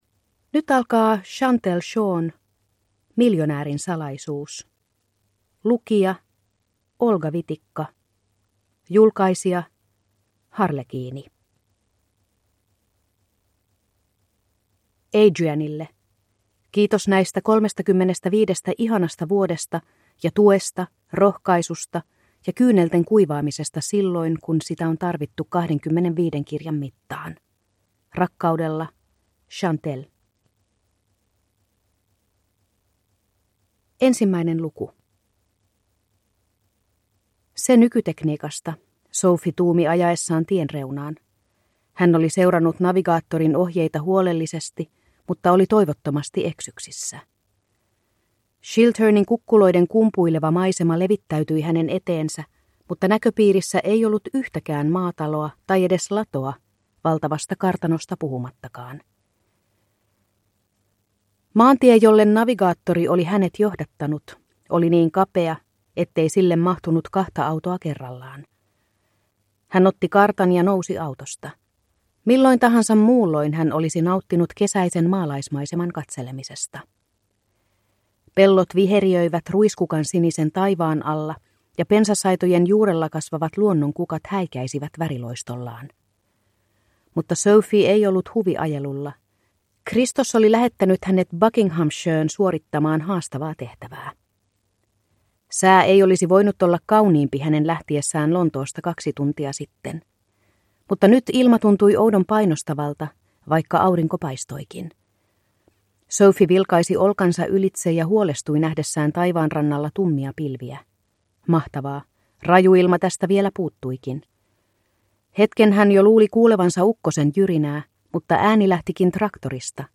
Miljonäärin salaisuus (ljudbok) av Chantelle Shaw